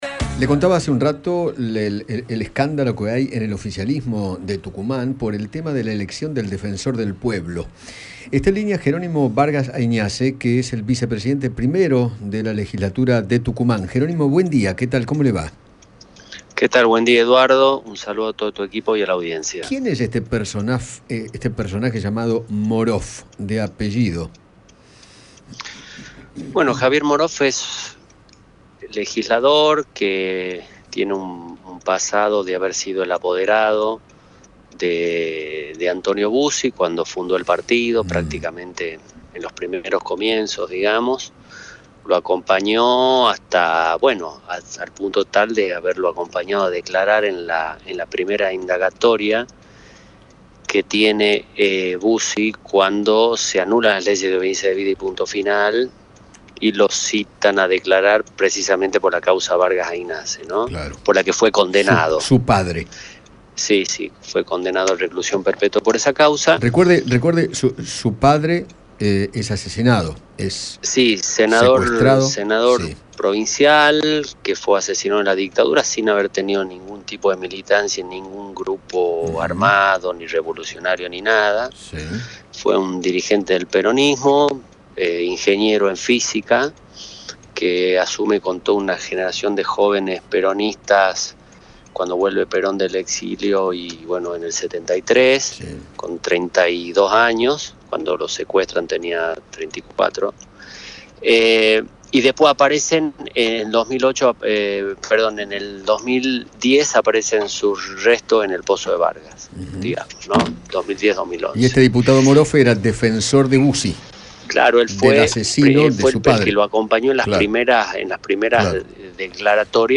Gerónimo Vargas Aignasse, hijo de Guillermo Vargas Aignasse, político desaparecido en 1976, y actual vicepresidente primero de la legislatura de Tucumán, dialogó con Eduardo Feinmann sobre la interna del oficialismo tucumano y el rol del legislador Javier Morof.